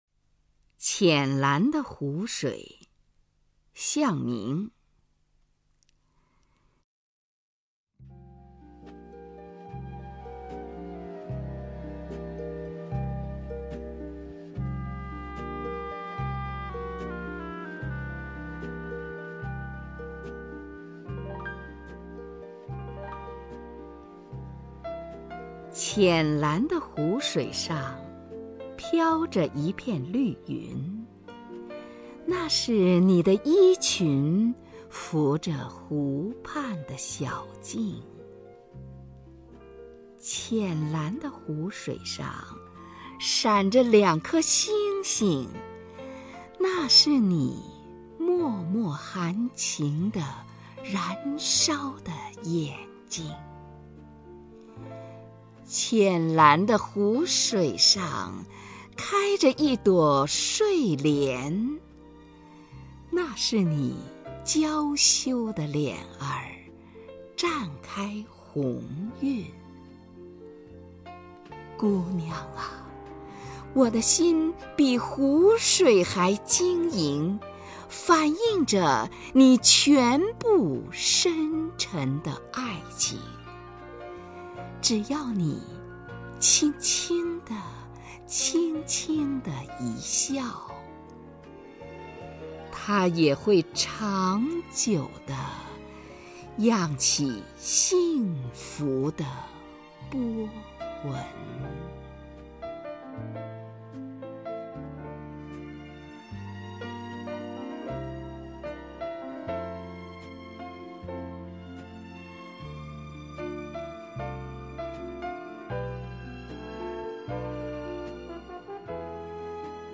首页 视听 名家朗诵欣赏 虹云
虹云朗诵：《浅蓝的湖水》(向明)　/ 向明